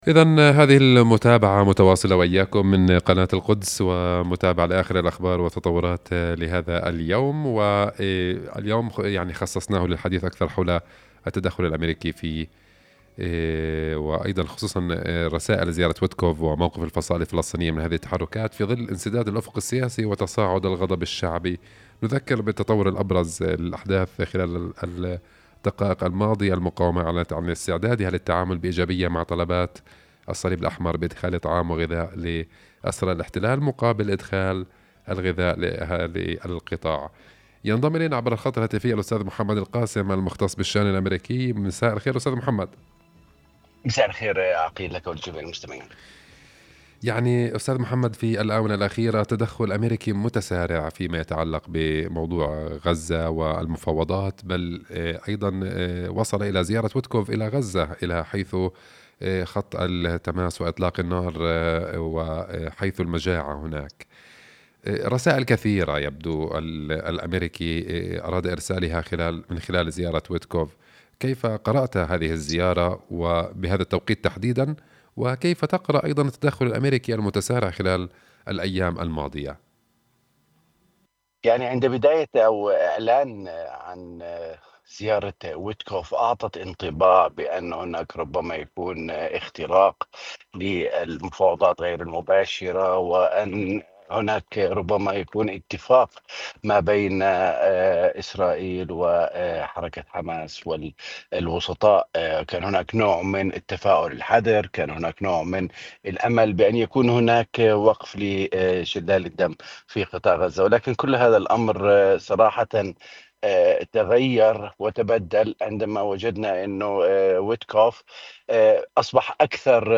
حديث إذاعي